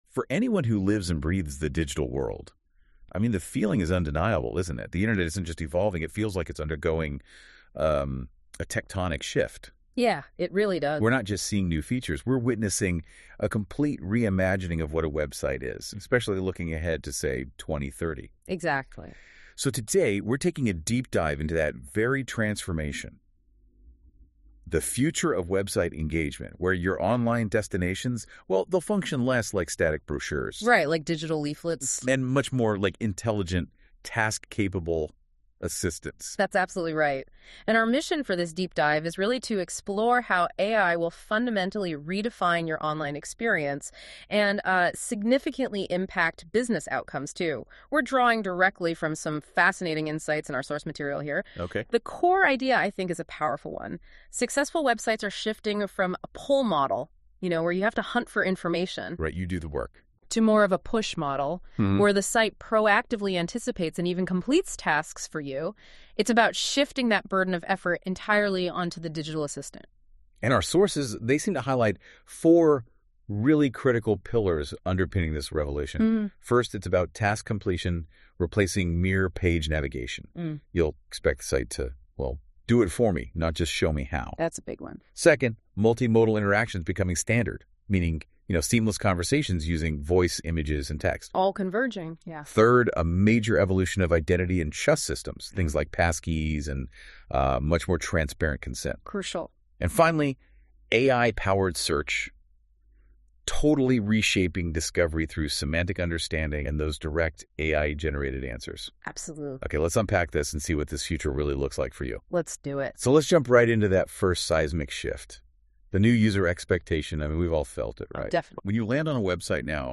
by AI. Yeah, it can do that too.